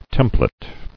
[tem·plate]